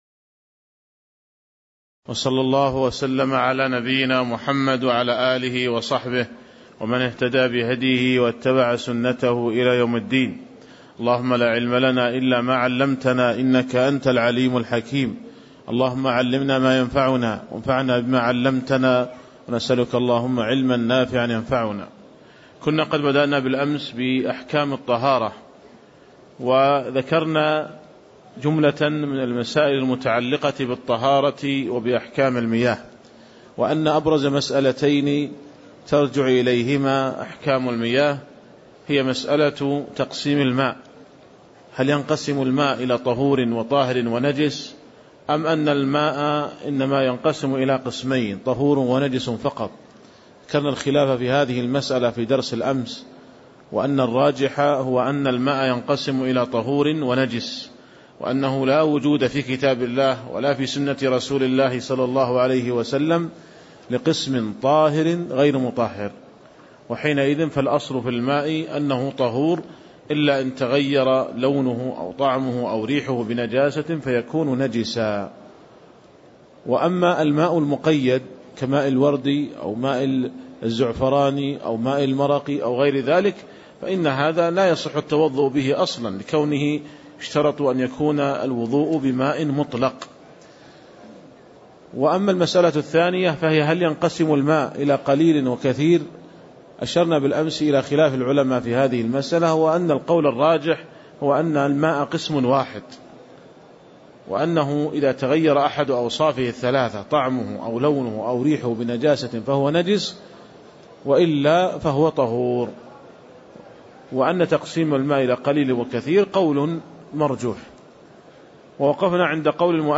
تاريخ النشر ١١ شوال ١٤٣٦ هـ المكان: المسجد النبوي الشيخ